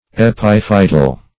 epiphytal - definition of epiphytal - synonyms, pronunciation, spelling from Free Dictionary Search Result for " epiphytal" : The Collaborative International Dictionary of English v.0.48: Epiphytal \E*piph"y*tal\, a. (Bot.) Pertaining to an epiphyte.